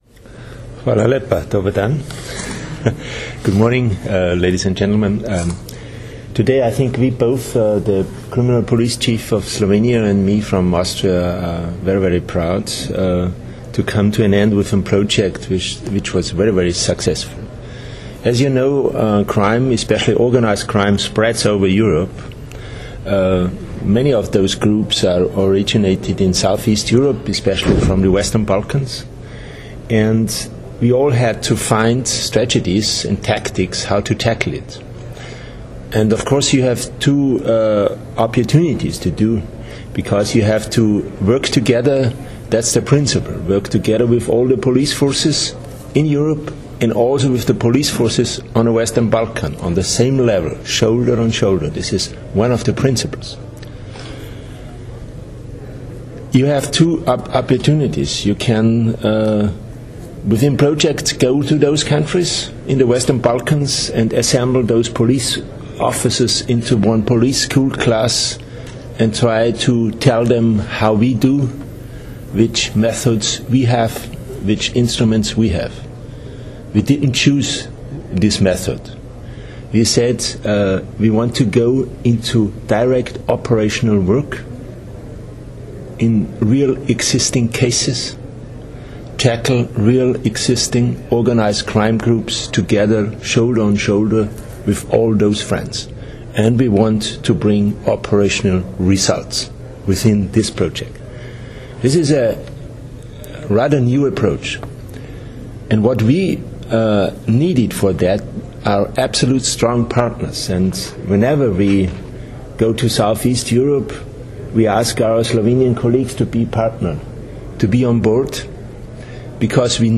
V Grand Hotelu Bernardin v Portorožu se je danes, 10. junija 2015, začela dvodnevna zaključna konferenca projekta WBOC - skupne preiskave z državami zahodnega Balkana v boju zoper organiziran kriminal in učinki na EU.
Zvočni posnetek govora Franza Langa,  (mp3)
direktorja avstrijske kriminalistične policije in namestnika generalnega direktorja za javno varnost